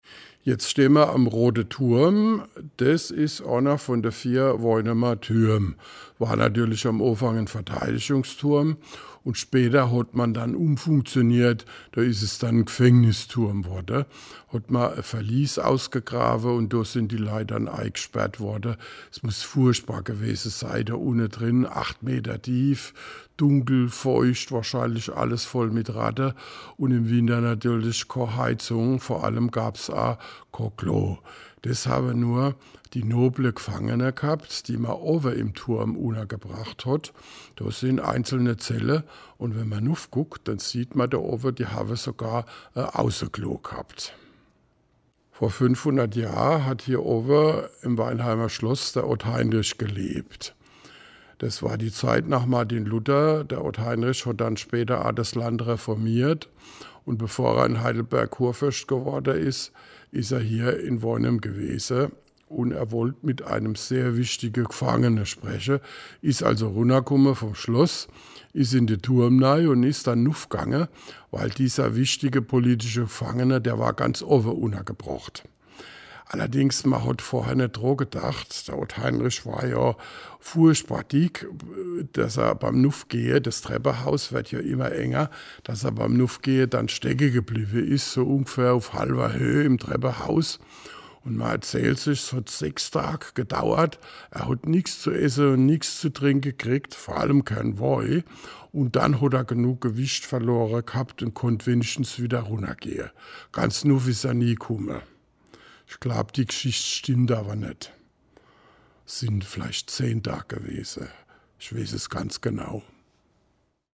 Mit Mundart unterwegs in der Altstadt
An 9 Stationen in der Innenstadt können Besucher über einen QR-Code mit dem Smartphone Anekdoten in Mundart anhören.